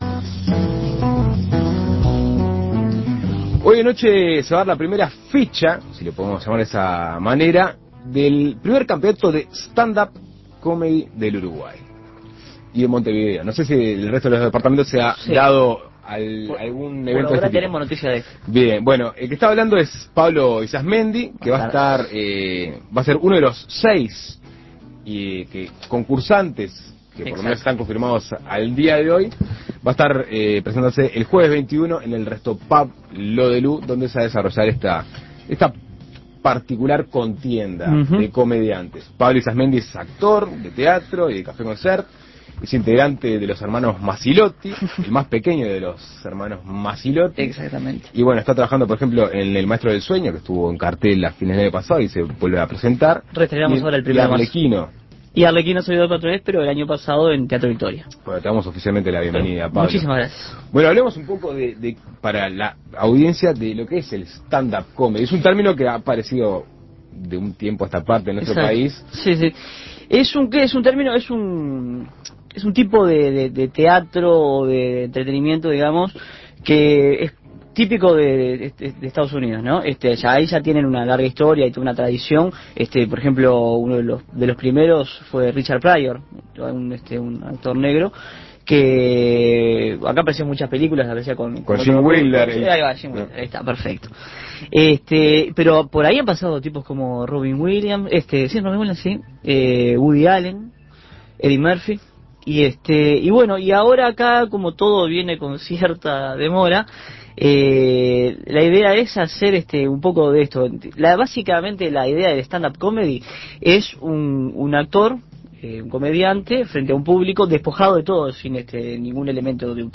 Entrevistas Primer campeonato de "Stand up Comedy" de Montevideo Imprimir A- A A+ Esta noche se dará la fecha inicial del primer campeonato de "Stand up Comedy" de Montevideo.